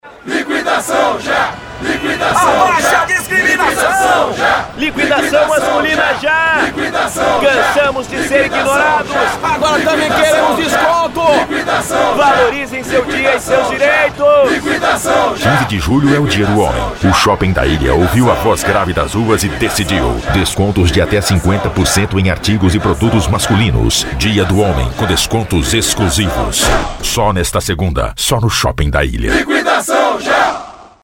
DMVoz Produções - Locução profissional